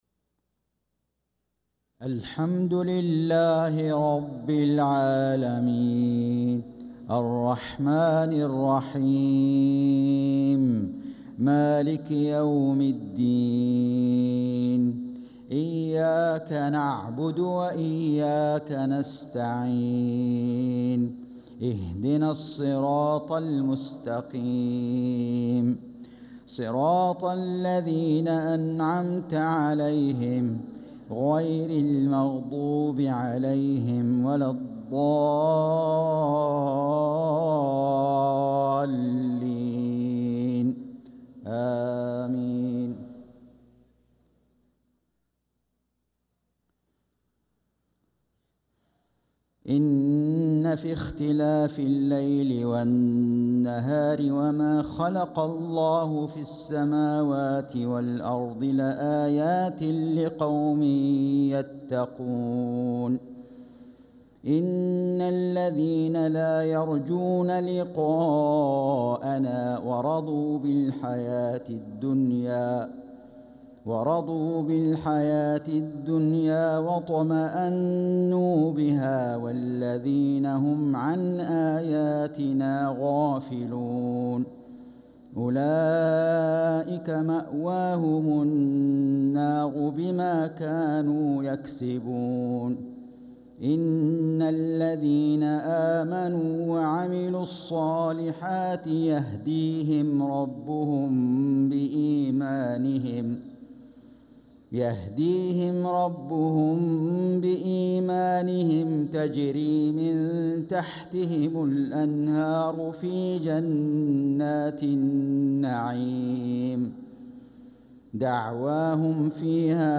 صلاة المغرب للقارئ فيصل غزاوي 15 ربيع الآخر 1446 هـ
تِلَاوَات الْحَرَمَيْن .